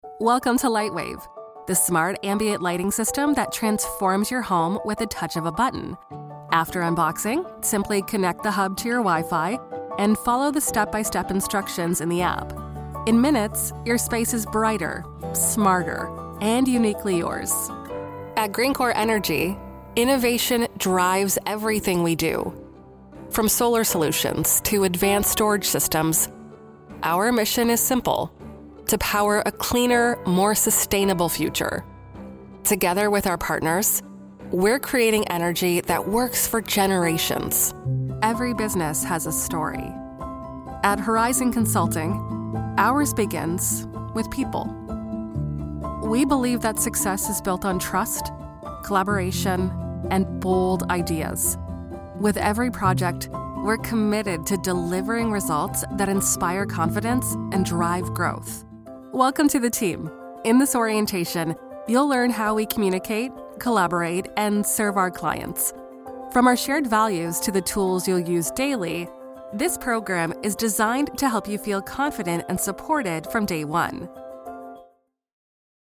Anglais (Américain)
Commerciale, Naturelle, Cool, Chaude
Corporate